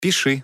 • Качество: 320, Stereo
короткие
голосовые